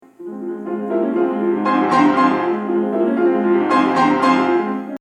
The finale, is turbulent, almost violent, explosive and virtuosic.
Its main motive is simple to the extreme: PAM PAM PAM. Repeatedly. That’s it:
(Again, sorry for the sound, it’s just a little mic in my practicing studio. But in two weeks I will record for real…)